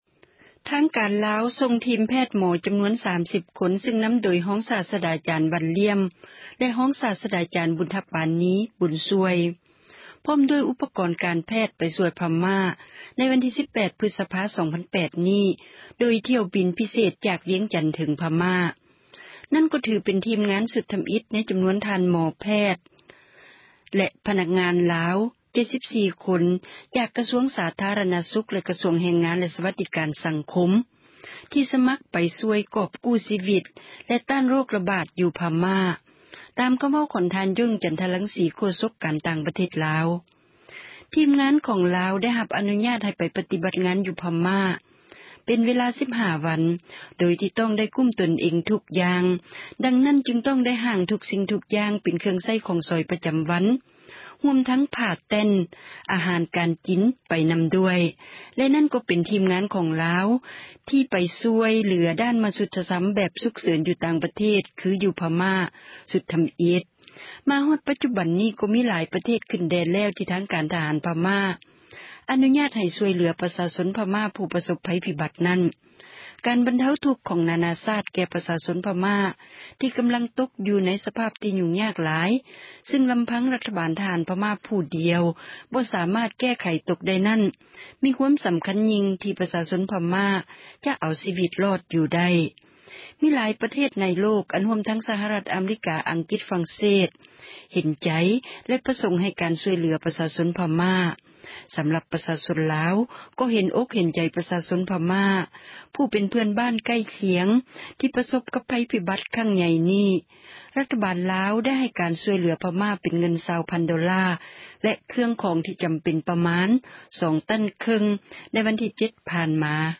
ຣາຍງານ